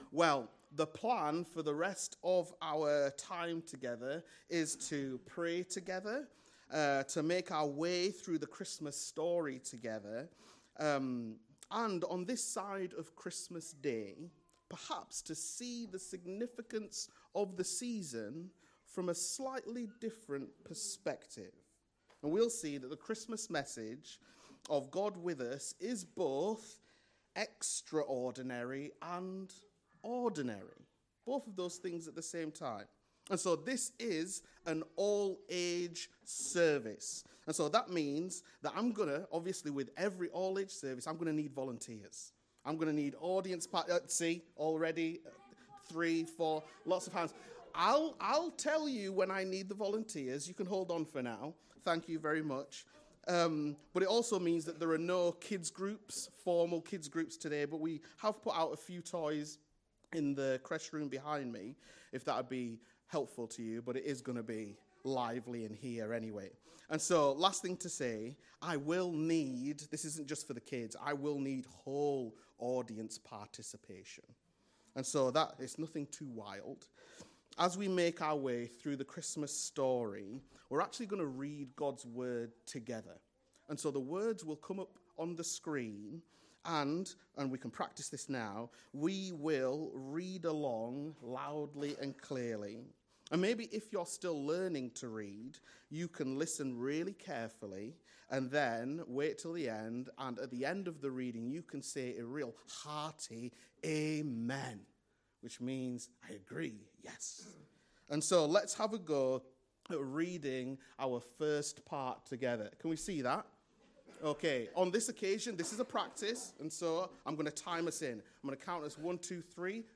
1 December 29 Sermon: The Weight of Silence 29:05